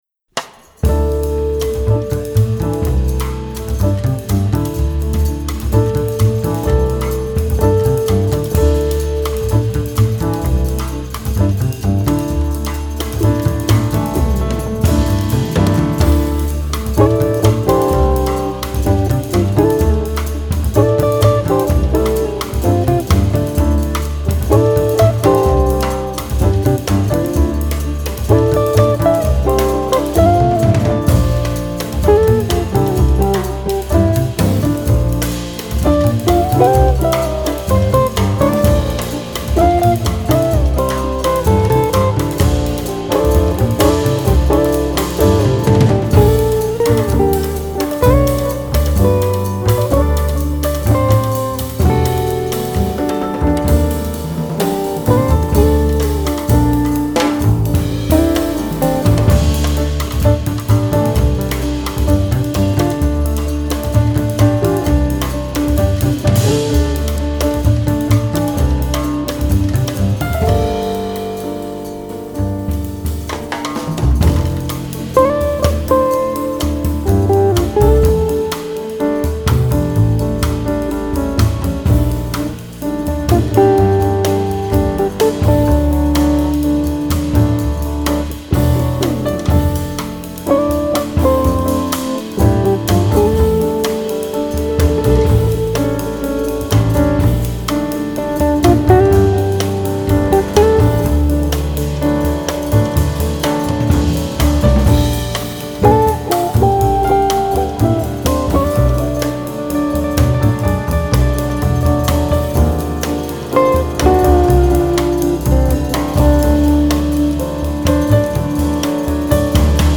album de jazz